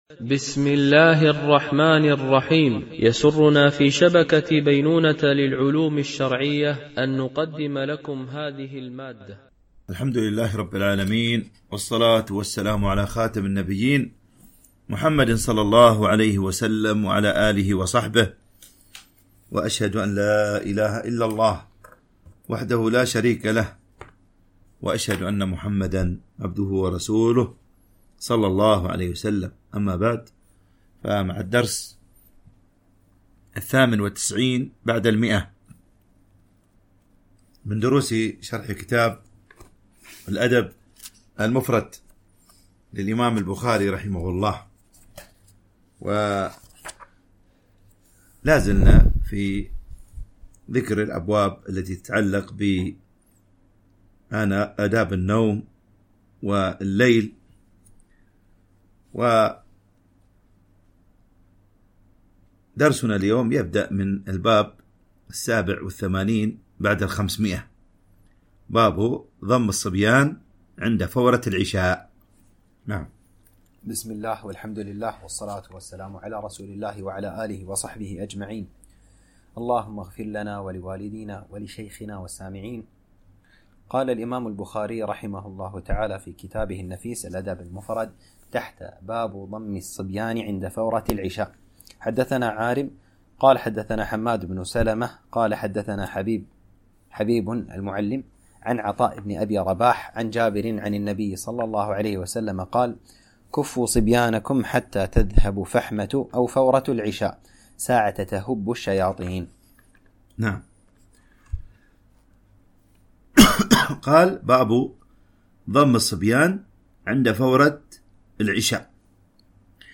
شرح الأدب المفرد للبخاري ـ الدرس 168 ( الحديث 1231 - 1237)